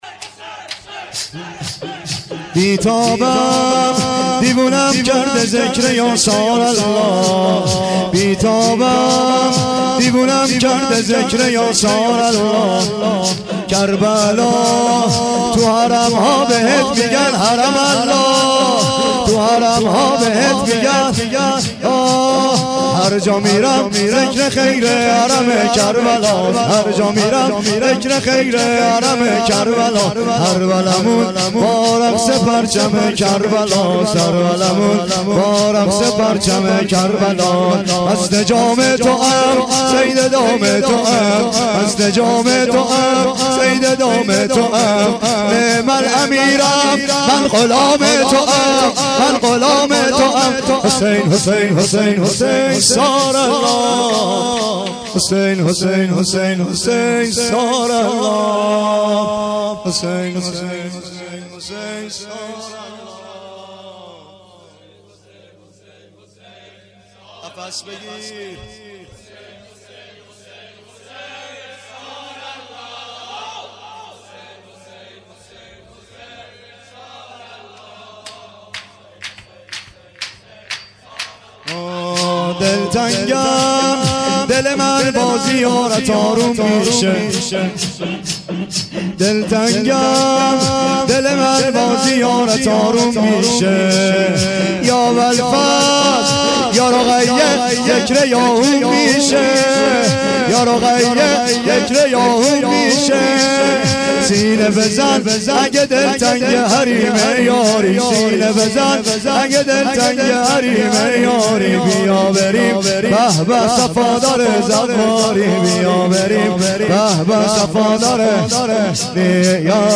بی تابم دیوونم کرده ذکر یا ثارالله(سینه زنی/شور